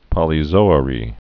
(pŏlē-zōə-rē) also pol·y·zo·ar·i·um (-zō-ârē-əm)